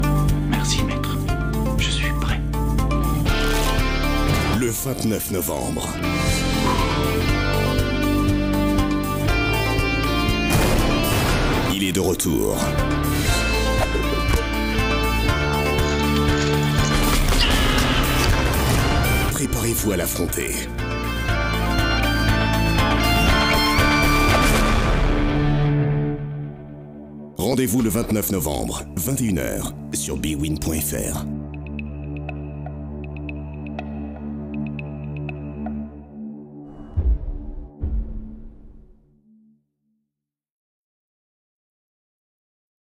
Prestation voix-off décalée et percutante pour Bwin avec Raymond Domenech
Voix style bande annonce blockbuster.
Publicité pour Bwin avec Raymond Domenech.
Dans la réalisation de ma mission, j’ai adopté une tonalité très grave, pour un rendu décalé, parodique, percutant et convaincant. J’ai recréé l’ambiance d’un blockbuster, où chaque moment est crucial et chaque mot compte. J’ai joué avec la virilité de la voix, tout en restant dans le ton décalé pour créer un contraste intéressant.